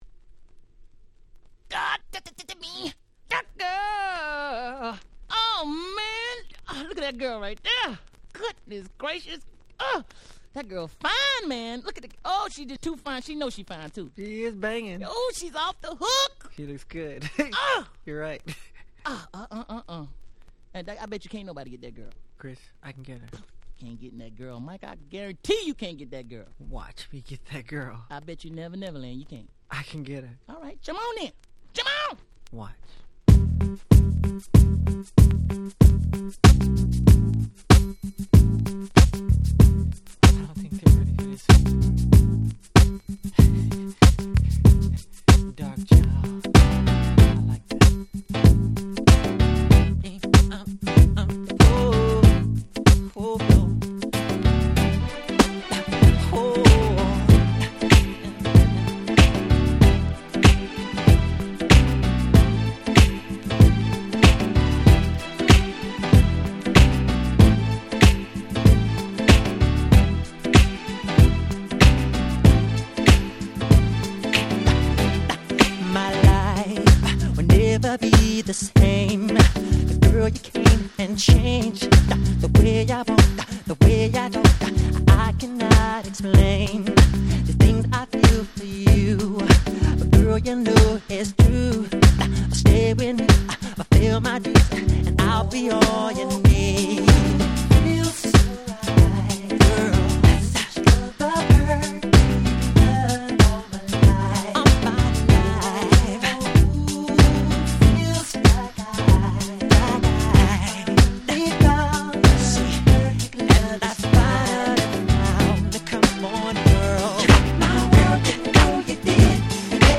01' 世界的大ヒットR&B♪
説明不要の00's R&B Clasicsです！！